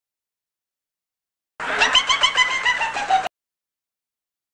Llorido del chavo del ocho
llorido-del-chavo-del-ocho.mp3